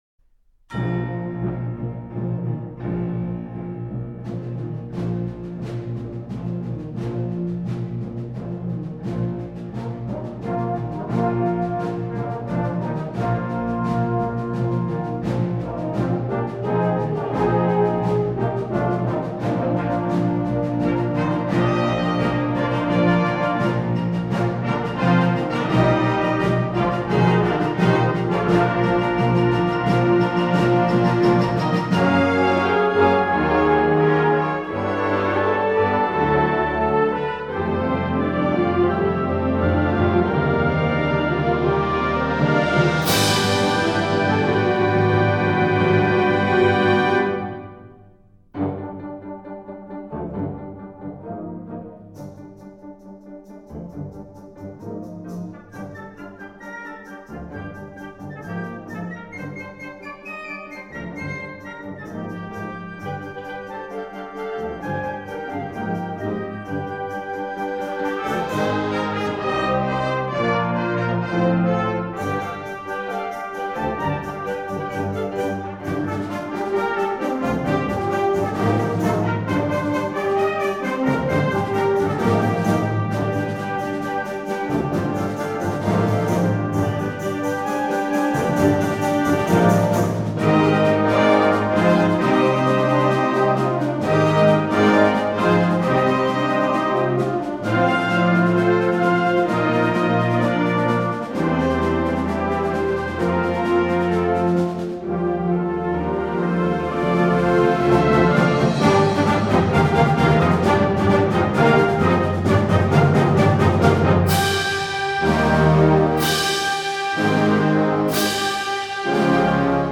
Voicing: Full Score